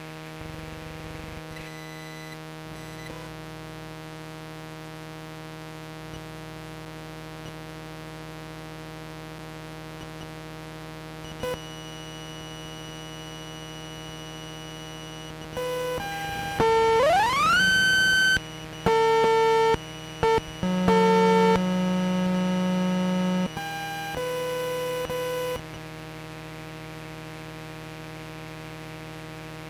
描述：This ATM is supposed to talk to blind users when they plug headphones. Unfortunatelly, it sounds like some game from 90s. The most interesting part is when you get the money rising tones, beeps and stuff. Recorded dyrectly via audio jack cable.
标签： buzz bank atm money beep
声道立体声